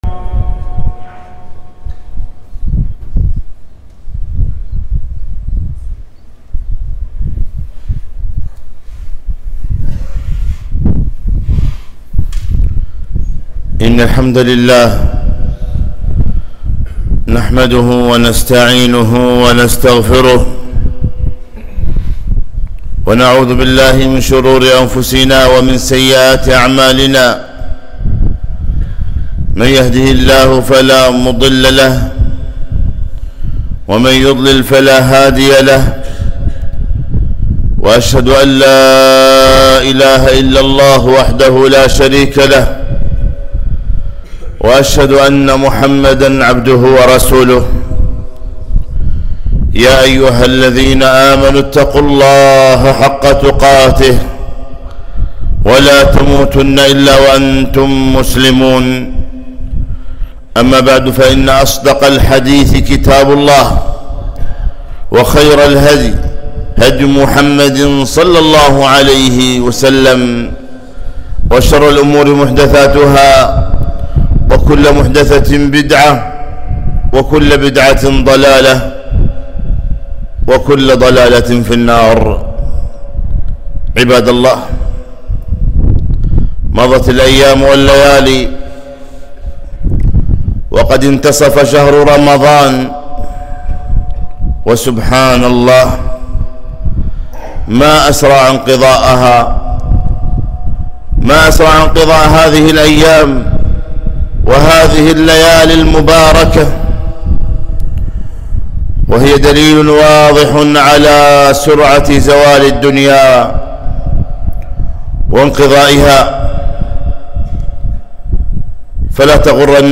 خطبة - وانتصف شهر رمضان